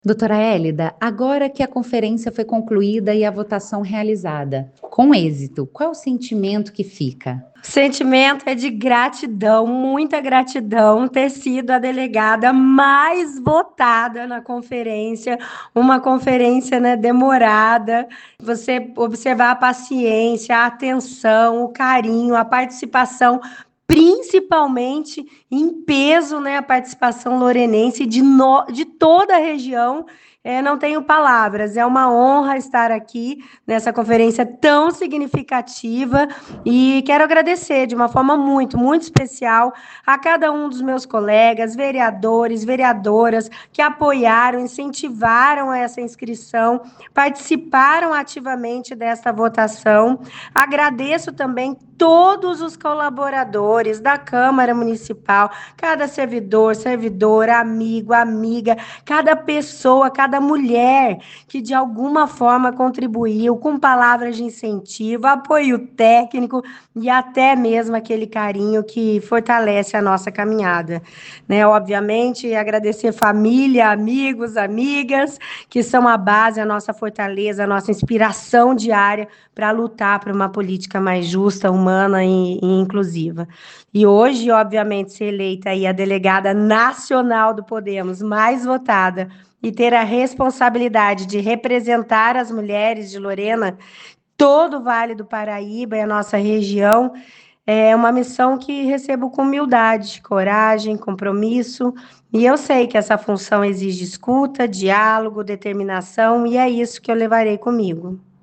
Entrevista (áudios):